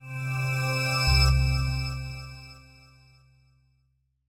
Здесь вы найдете плавные затухания, исчезающие эффекты и другие варианты финальных аудиоштрихов.
Концовка (outro) — популярный вариант завершения фильма, который размещают после титров